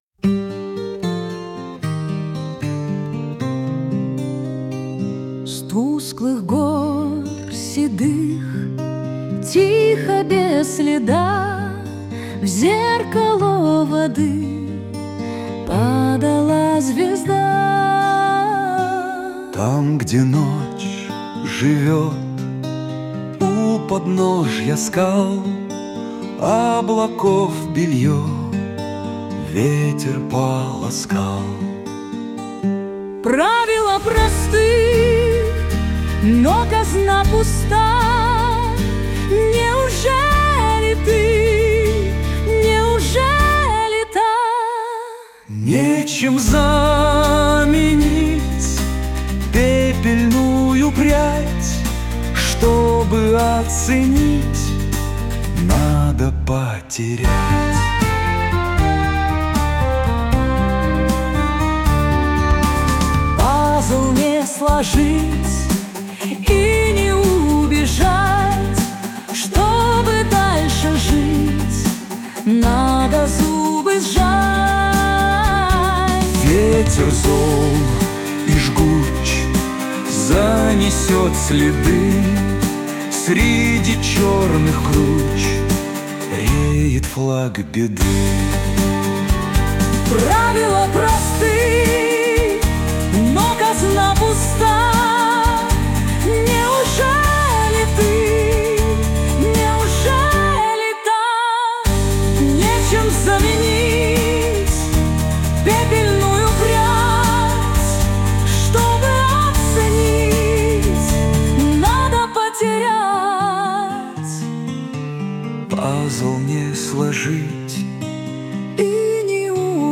ß ñòàðï¸ð, ïî÷èòàë òóò îáñóæäåíèå íåéðîñåòåé è ïîäóìàë: "À ÷î, à âäðóã?" è ïîïðîáîâàë îçâó÷èòü âîò ýòîò, âûëîæåííûé òåêñò, â Ñóíî.
è óäàðåíèÿ òèïà ñðÅäè, íàâåðíîå, òîæå ïîíðàâèëèñü)
Suno ñêðåàòèâèëî, åñëè á ñàì - óäàðåíèÿ áû íå óïëûëè.
À òàê-òî ìåëîäüêà ñèìïàòè÷íàÿ âûøëà. Íå õèòîâî, íî ÷òî-òî â íåé åñòü äóøåâíîå.